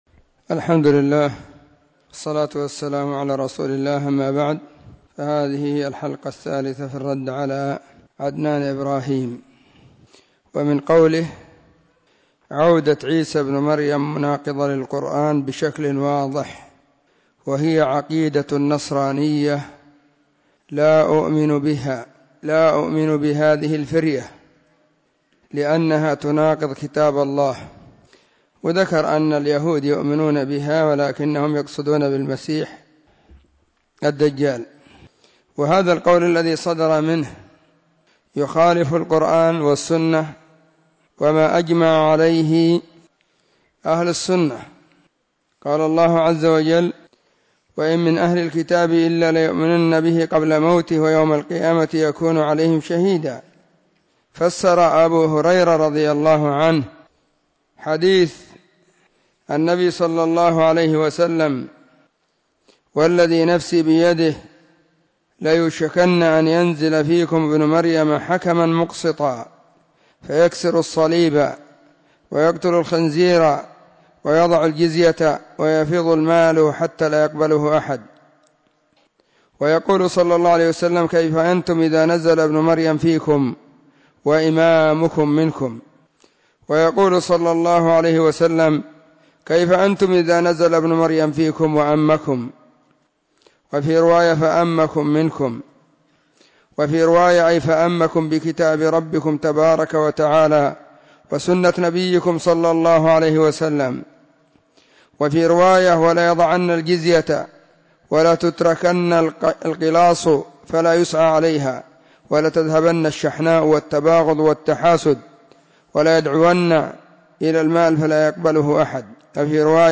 📢 مسجد الصحابة – بالغيضة – المهرة – اليمن حرسها الله.
الخميس 22 ربيع الأول 1443 هــــ | الردود الصوتية | شارك بتعليقك